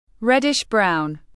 Reddish-brown /ˈred.ɪʃ braʊn/